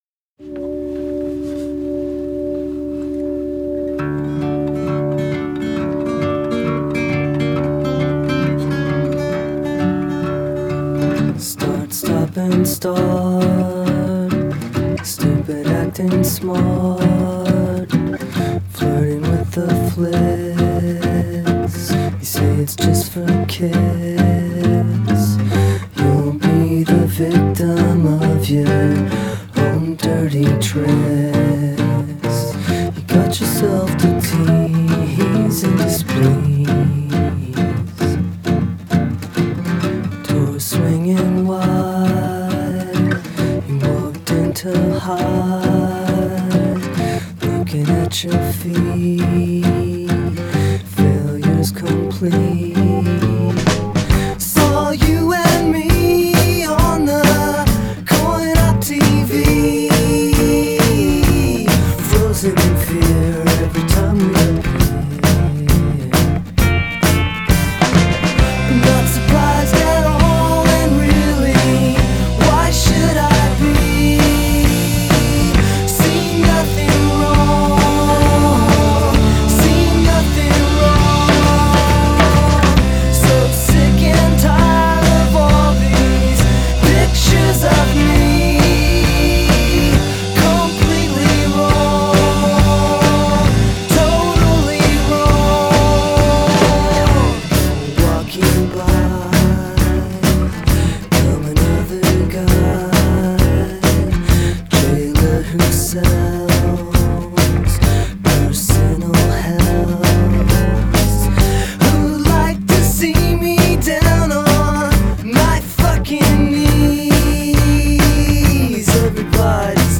Genre: Indie Rock / Singer-Songwriter / Acoustic